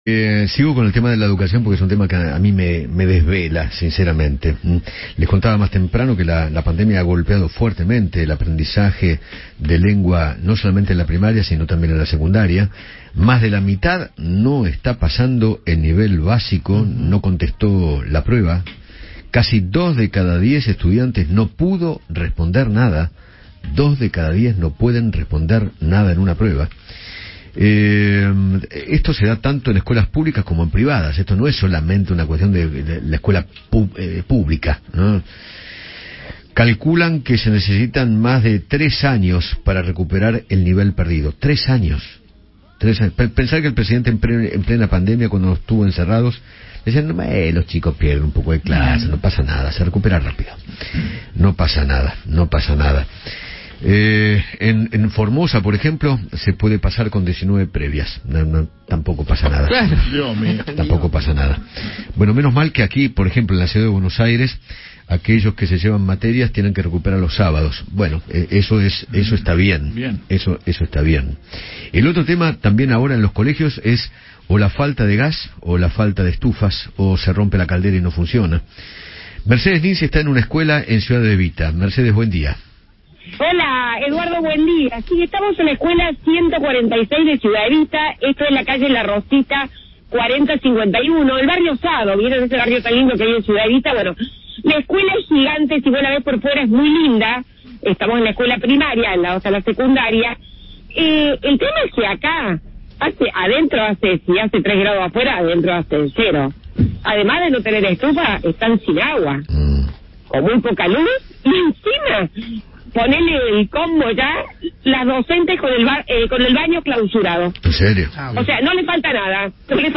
conversó con Eduardo Feinmann sobre el mal estado en el que se encuentran las escuelas en la Provincia de Buenos Aires.